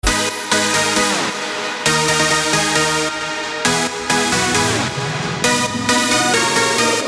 • Качество: 320, Stereo
громкие
Electronic
электронная музыка
без слов
Стиль: big room